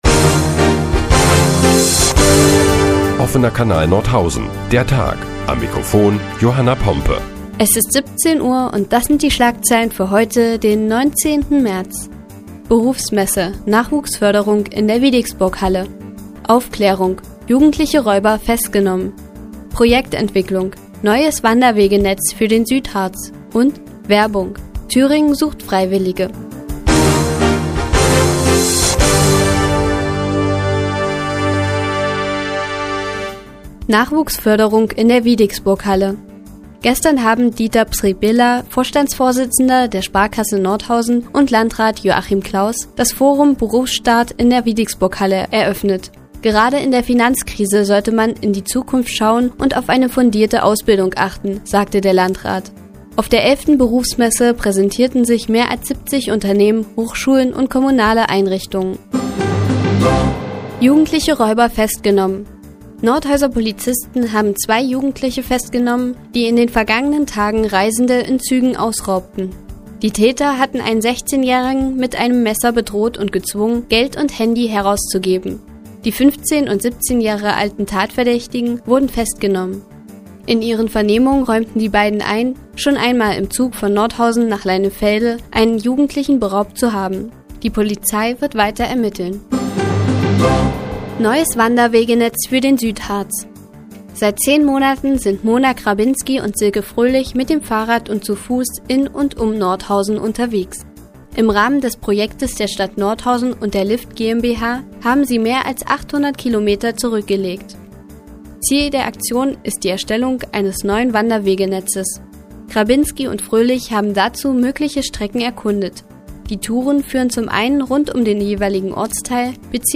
Die tägliche Nachrichtensendung des OKN ist nun auch in der nnz zu hören. Heute geht es unter anderem um die Festnahme jugendlicher Räuber und einem Aufruf zum freiwilligen Jahr.